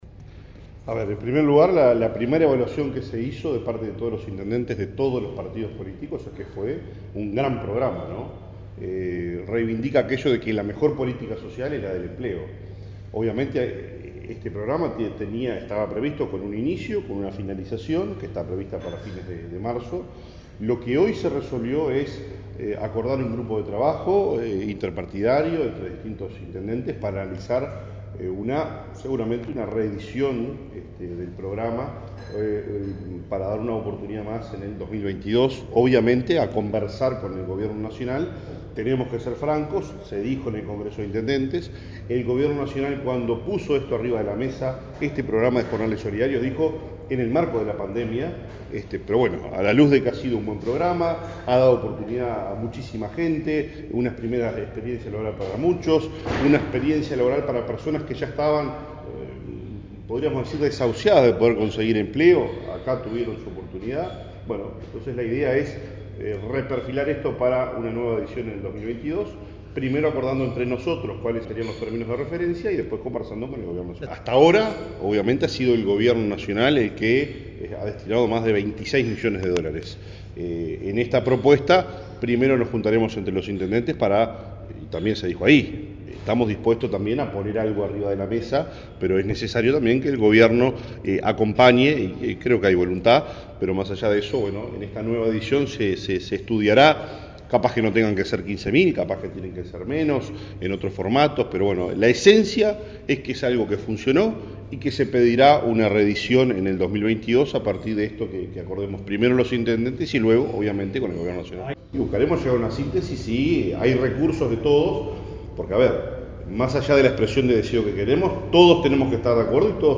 El intendente de Salto, Andrés Lima, expresó en rueda de prensa que “los tres intendentes del frente amplio presentaron a consideración del congreso, que la propuesta del programa oportunidad laborar se mantenga durante el año 2022, que en principio termina el 30 de marzo”.
Por su parte, Nicolás Olivera, intendente de Paysandú dijo que “la primer evaluación por parte de todos los intendentes políticos, dice que fue un gran programa, reivindica que la mejor política social es el empleo”.